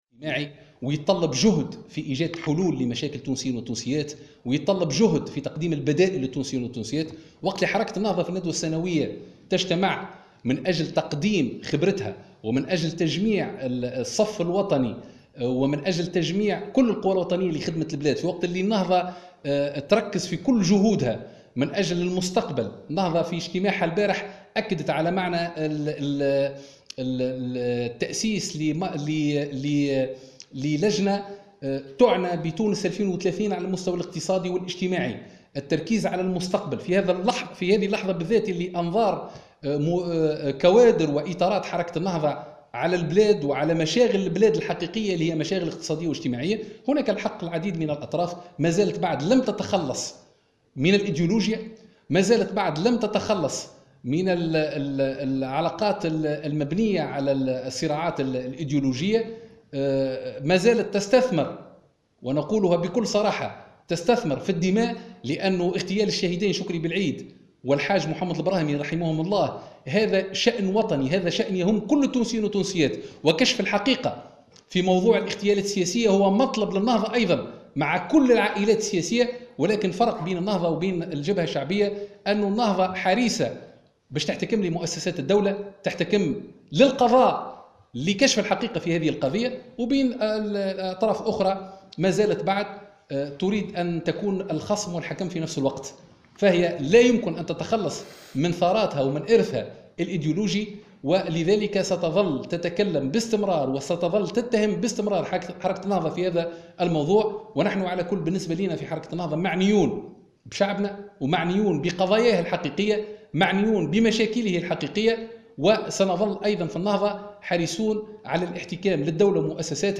علق الناطق الرسمي باسم حركة النهضة عماد الخميري في تصريح لمراسلة الجوهرة "اف ام" اليوم الأحد على هامش أعمال الندوة السنوية الثانية لإطارات الحزب على الاتهامات الموجهة للنهضة من قبل لجنة الدفاع عن الشهيدين بلعيد والبراهمي.